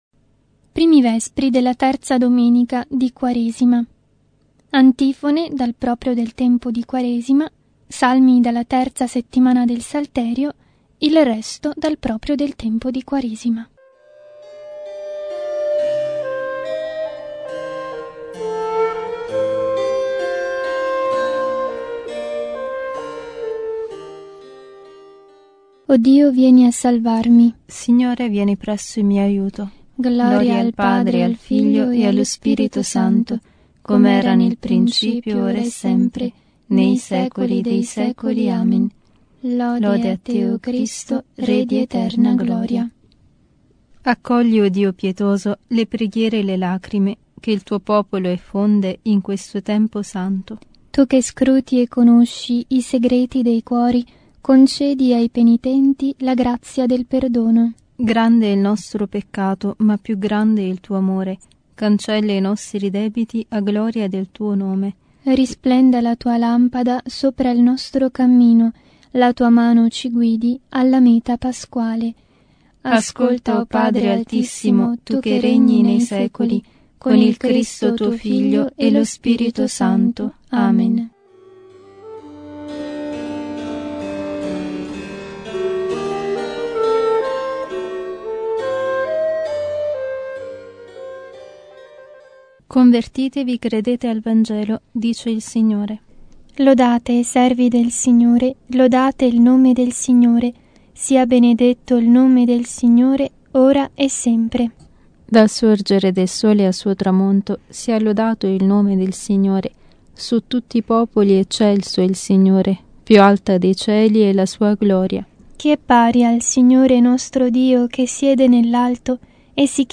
Liturgia delle Ore